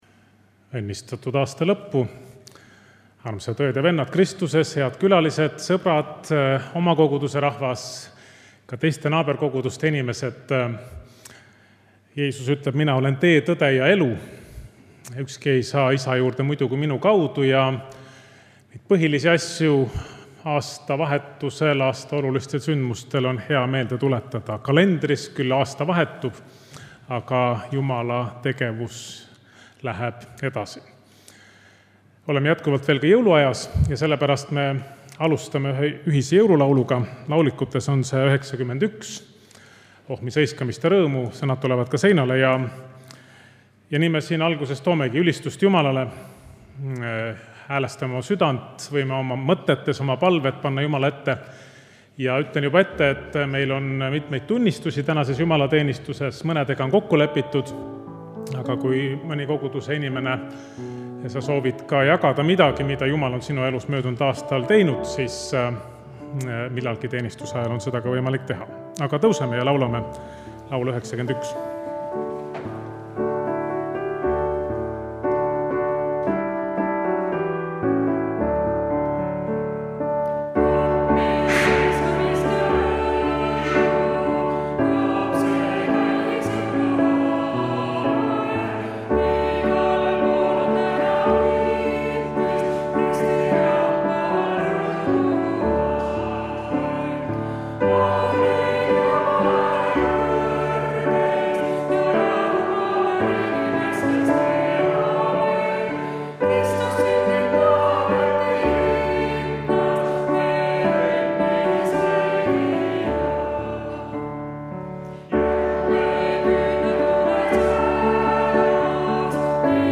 Tunnistused
Jutlus